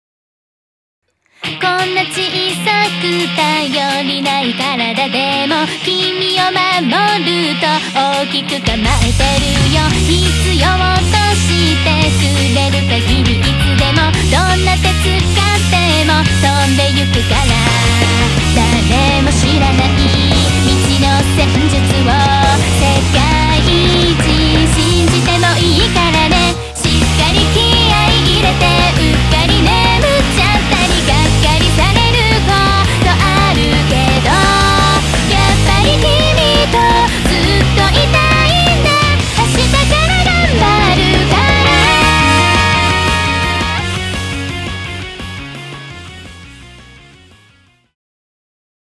クロスフェードデモ
ポップあり、バラードあり、涙あり、情熱込みの8曲となっております！
春の空に華と奏でる、東方フルボーカルポップをどうぞお楽しみください！